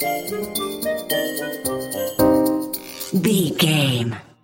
Aeolian/Minor
percussion
flute
orchestra
piano
silly
circus
goofy
comical
cheerful
perky
Light hearted
sneaking around
quirky